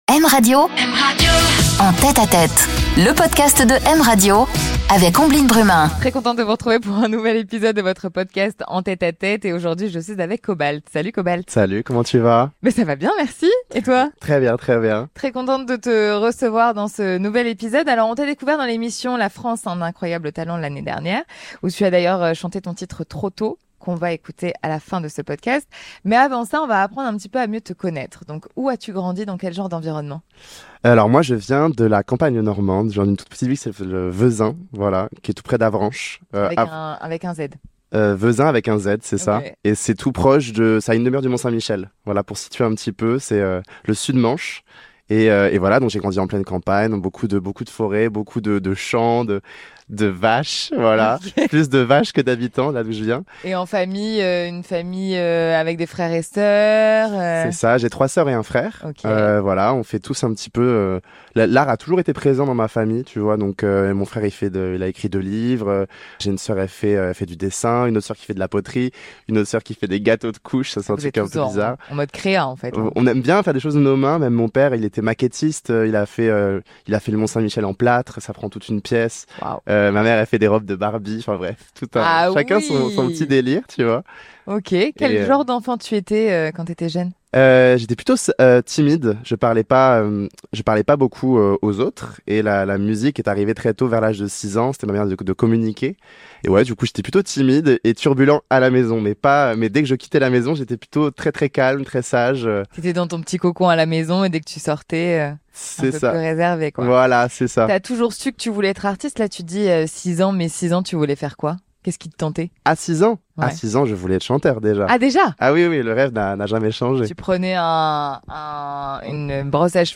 Une interview en confidence, dans l'intimité des artistes Une radio qui défend la chanson française, c'est une radio qui défend les nouveaux talents français